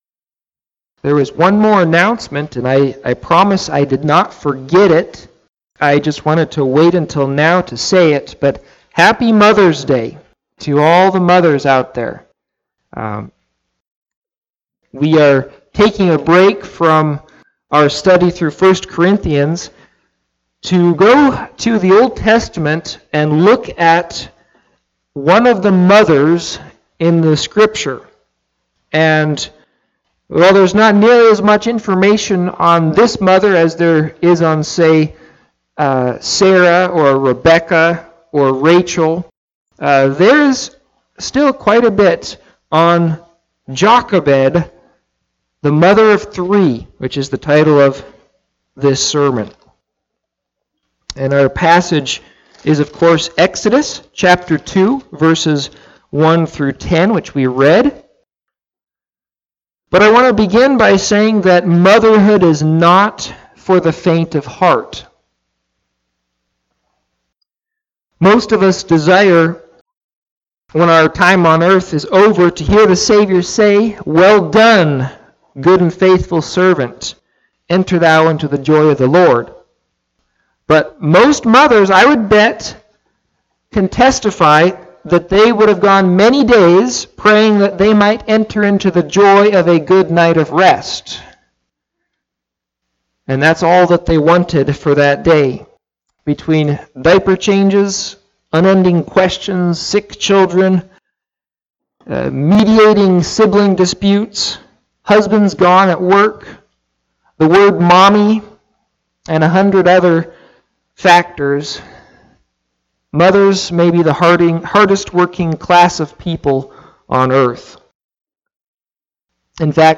Listen to Audio of the sermon or Click Facebook live link above.